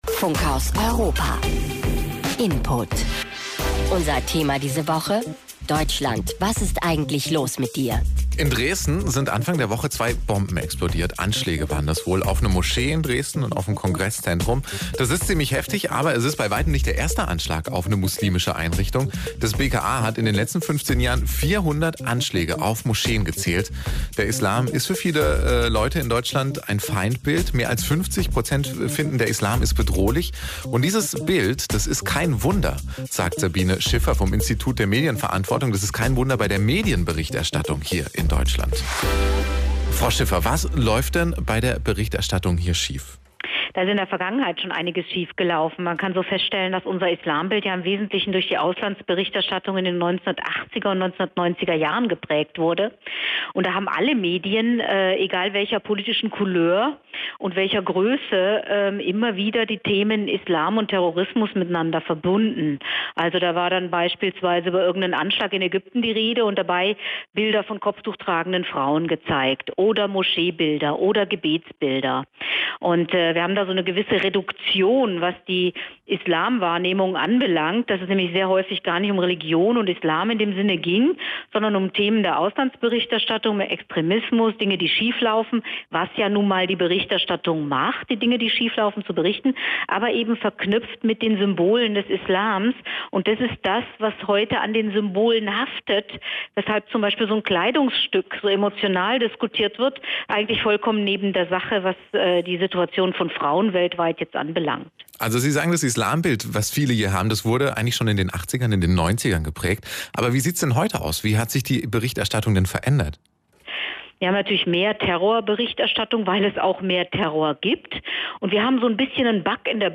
Interview: Was läuft falsch in der Berichterstattung?
WDR, Funkhaus Europa, Interview vom 29.09.2016